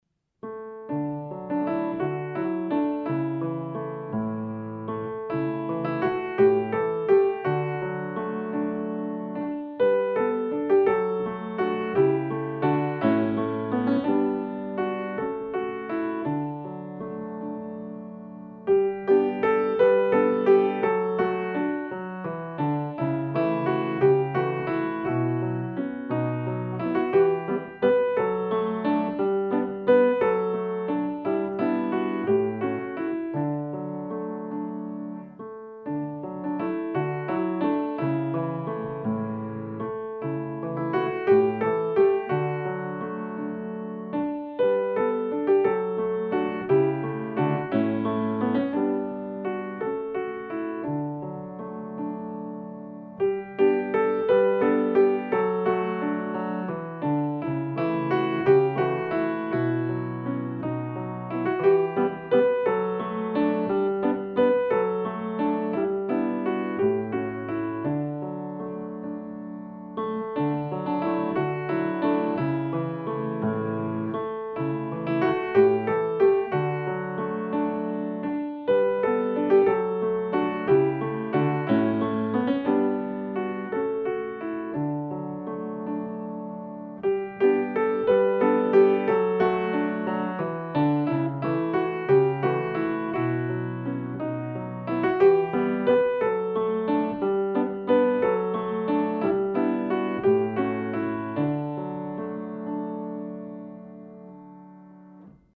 Hier findest du die Klavierbegleitung